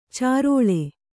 ♪ cāroḷe